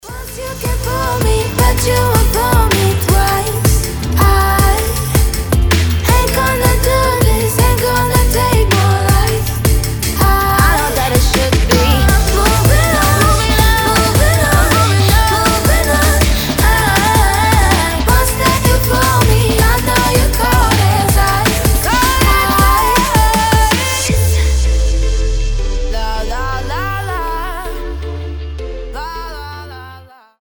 ритмичные
мелодичные
женский голос
драм энд бейс